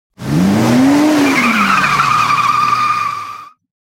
Sound Effects / Street Sounds 12 May, 2025 Car Acceleration With Screeching Tires Sound Effect Read more & Download...
Car-acceleration-with-screeching-tires-sound-effect.mp3